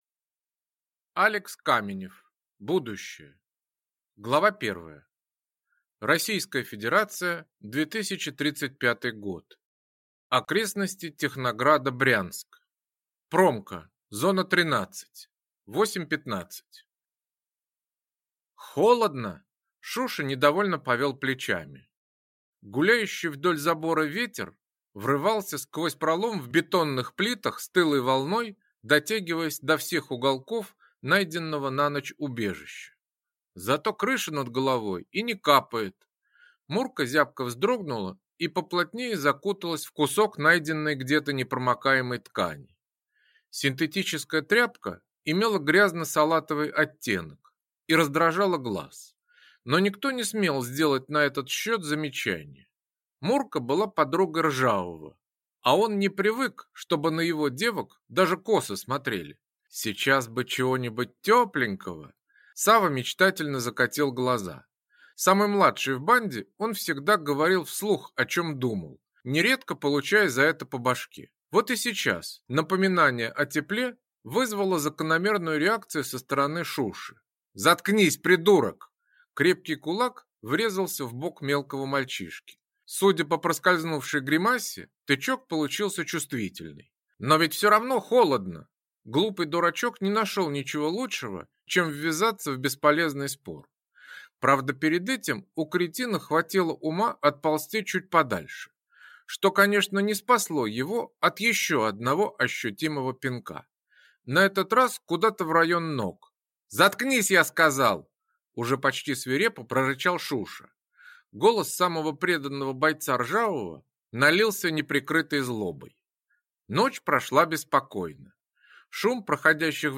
Аудиокнига Будущее | Библиотека аудиокниг